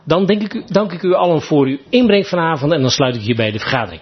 Geluidsverslag raadsvergadering 2 november 2022